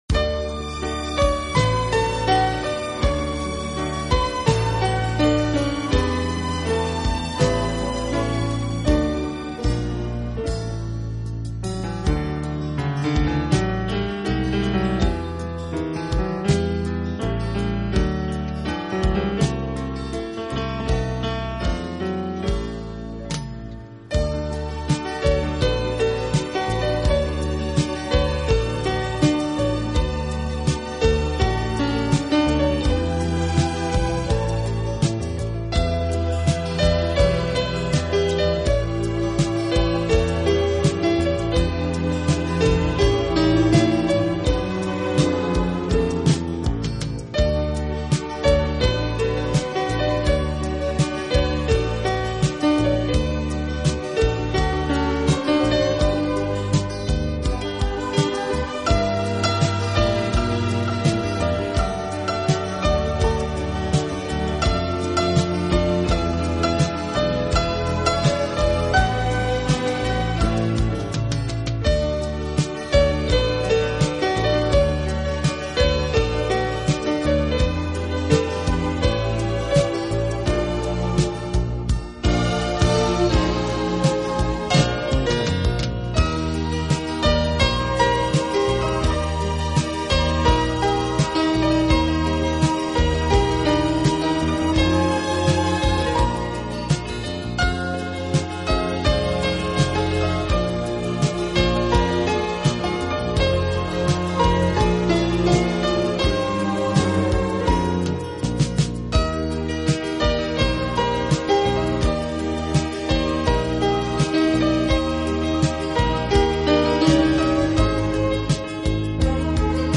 音乐类型：CD古典跨界音乐